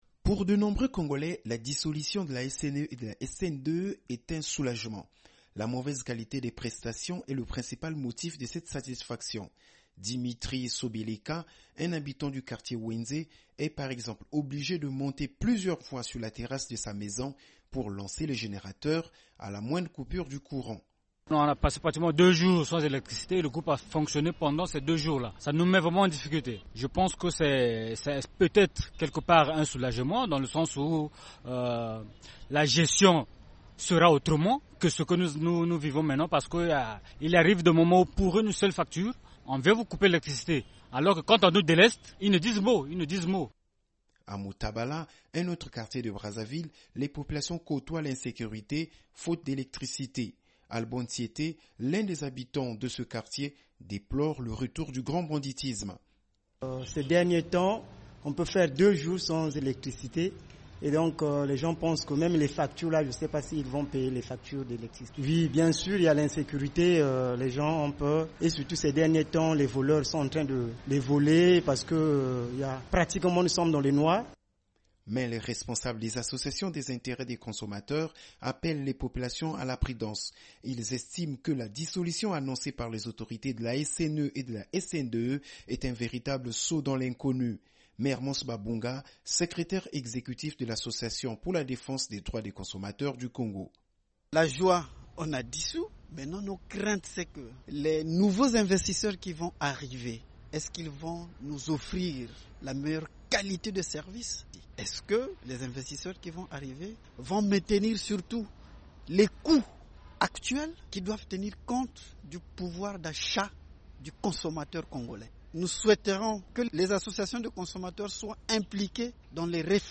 Reportage
Brèves Sonores